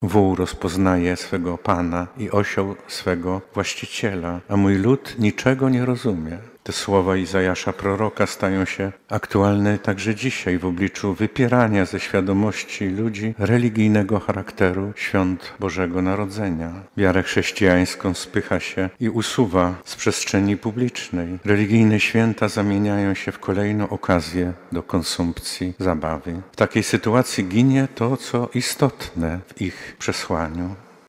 W Boże Narodzenie objawia się nam Bóg bez majestatu i potęgi, leżący bezbronny w łożu – mówił podczas mszy odprawionej w Boże Narodzenie arcybiskup Stanisław Budzik. Metropolita lubelski w czasie kazania zwrócił też uwagę na symbolikę stajenki i znajdujących się w niej zwierząt.
CZYTAJ: Pasterka rozpoczęła liturgię Bożego Narodzenia [ZDJĘCIA] Nabożeństwo odprawiono w archikatedrze lubelskiej.